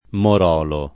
[ mor 0 lo ]